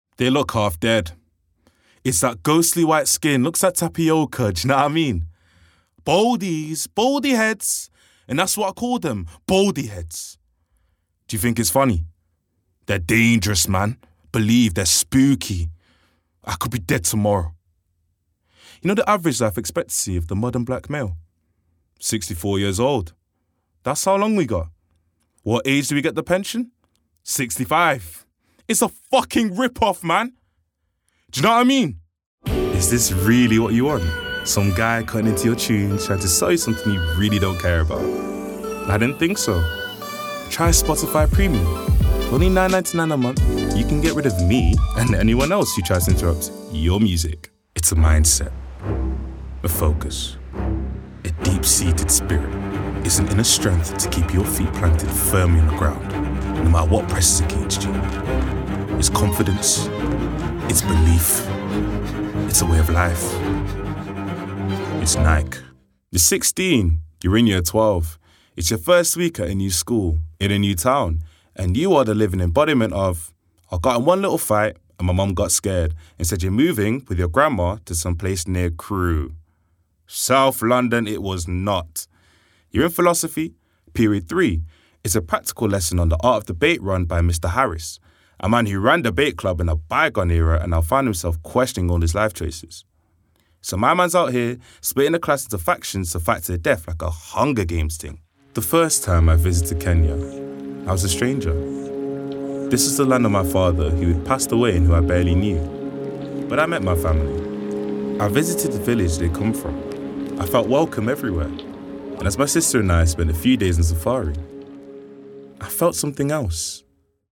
Stoke-on-Trent / MBE
Voicereel:
Baritone